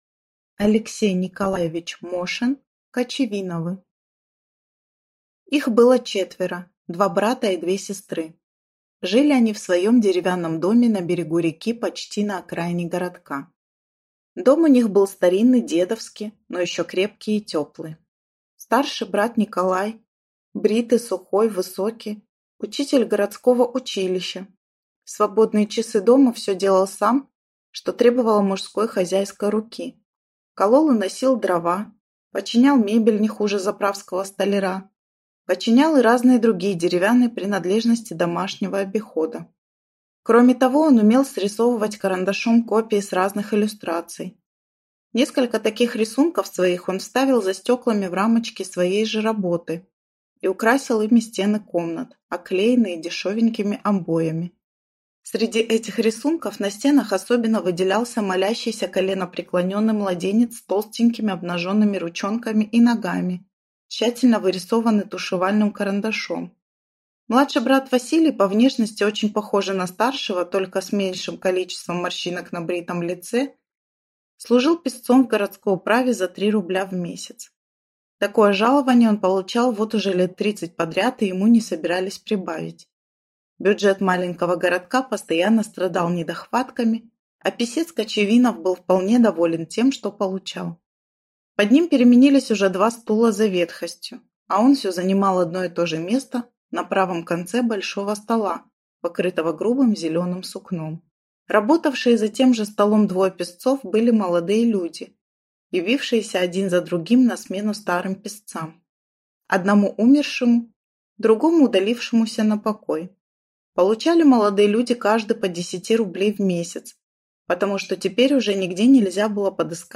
Аудиокнига Кочевиновы | Библиотека аудиокниг